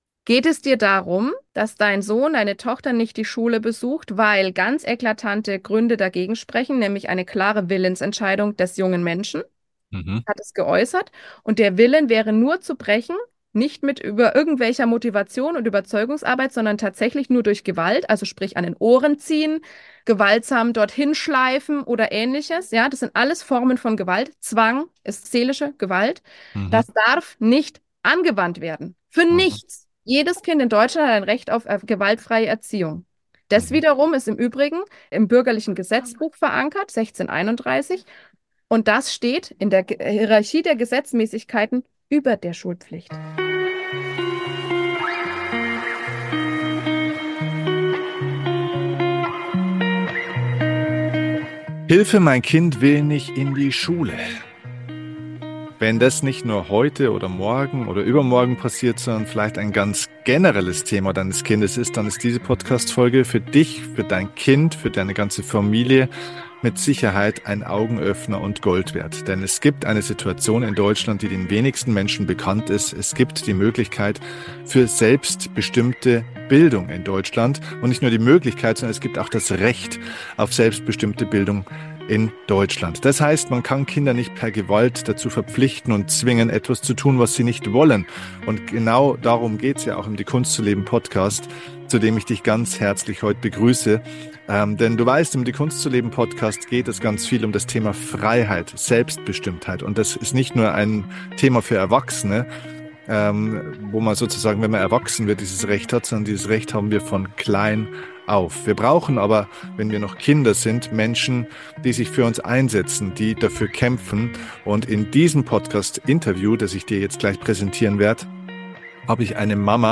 In diesem inspirierenden Gespräch geht es um Verantwortung, Klarheit und Vertrauen zu uns selbst und zur Entwicklung ins Kind – die Schlüssel zum Erfolg auf dem Weg zur freien, selbstbestimmten Bildung.